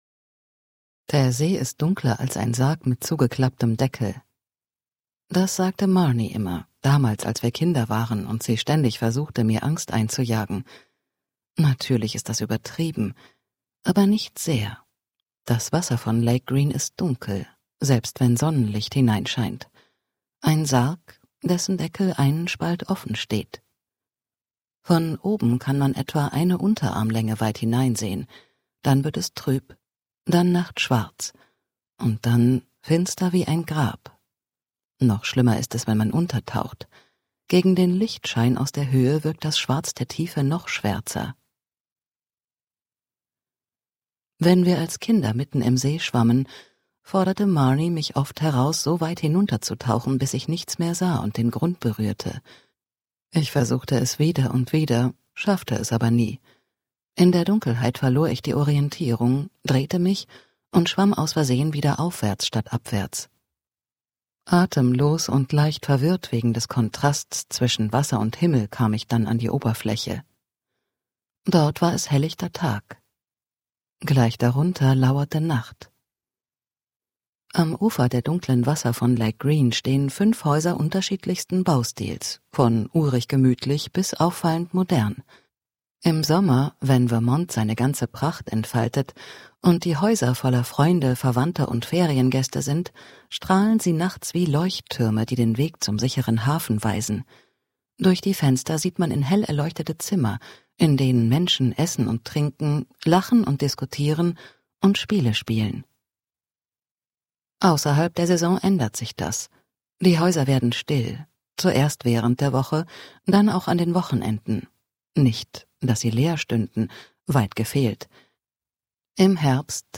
2025 | 1. Auflage, Ungekürzte Lesung